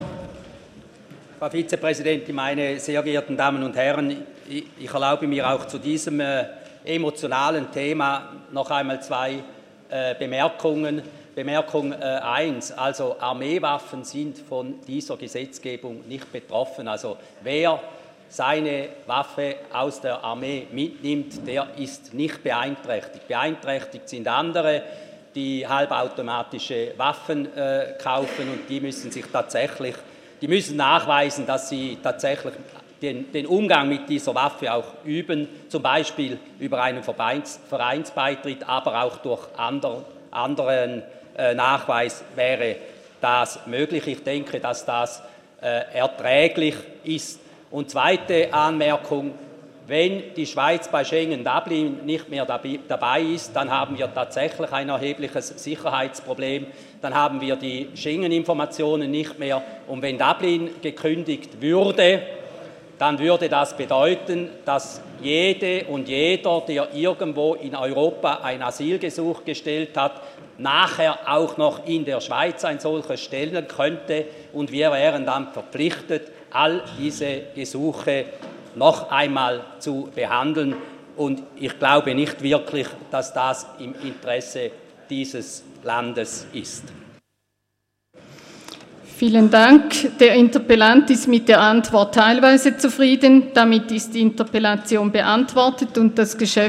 28.11.2017Wortmeldung
Regierungspräsident: Ich erlaube mir auch zu diesem emotionalen Thema zwei Bemerkungen:
Session des Kantonsrates vom 27. und 28. November 2017